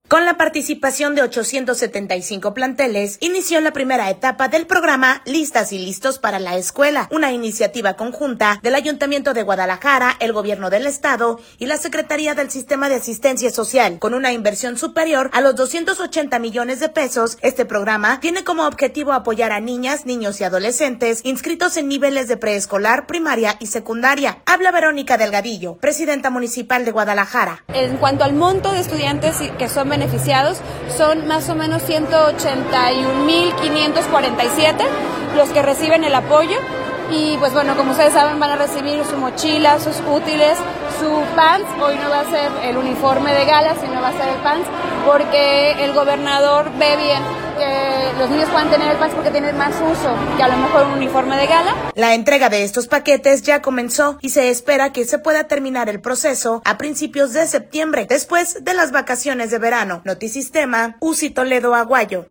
Habla Verónica Delgadillo, presidenta municipal de Guadalajara.